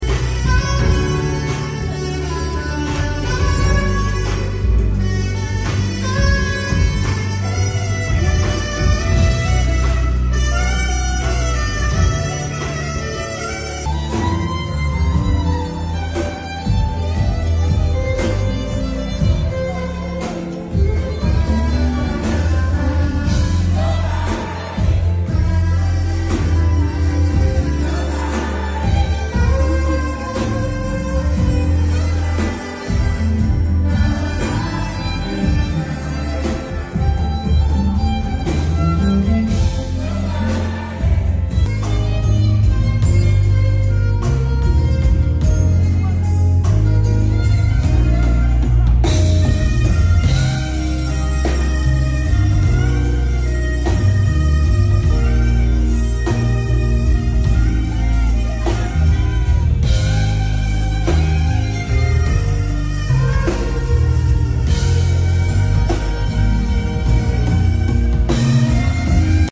birthday concert